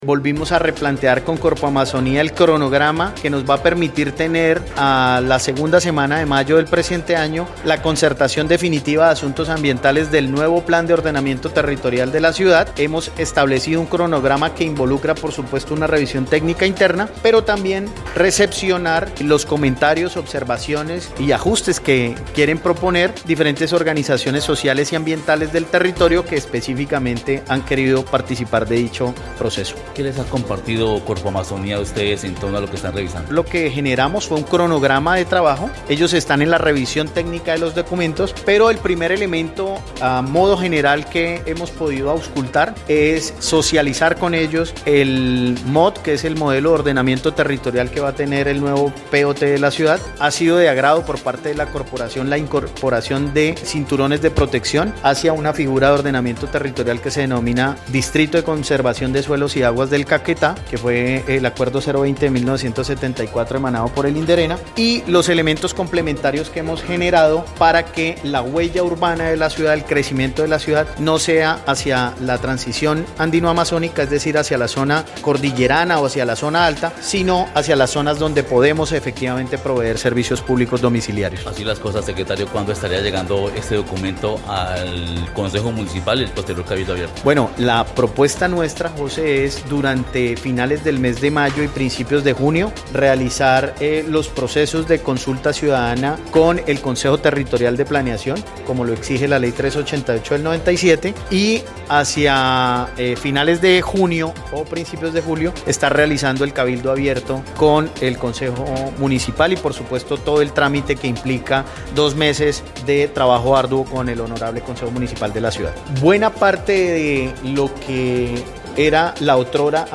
Luis Manuel Espinosa Calderón, secretario de planeación del municipio, explicó que, a Corpoamazonía le llamó la atención el Modelo de Ordenamiento Territorial, inmerso en el POT, donde se preservan las zonas de cordillera y las áreas de expansión urbanas apuntan hacia otras áreas donde se garantizan servicios públicos.